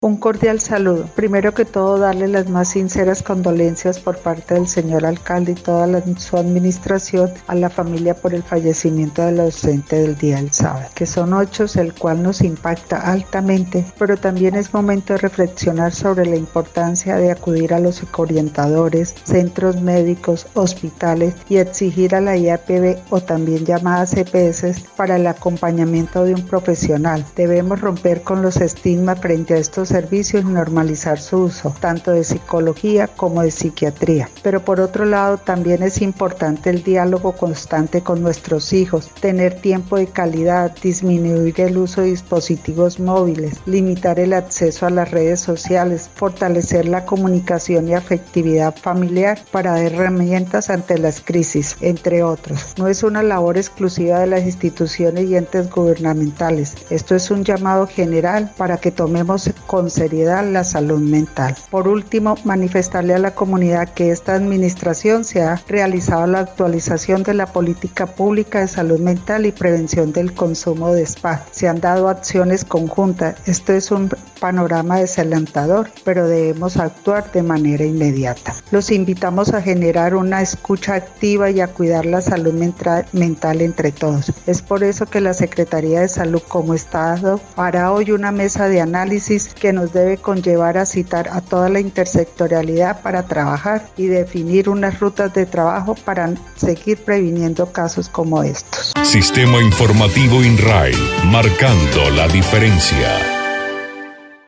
Sobre el caso, la secretaria de salud municipal, Gladis Caicedo Traslaviña, llamó la atención de los padres de familia para estar más atentos de los hijos menores de edad, y no desechar la idea de solicitar atención especializada, ya sea por psicología o psiquiatría.